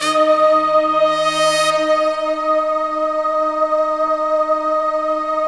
SI1 BRASS0DR.wav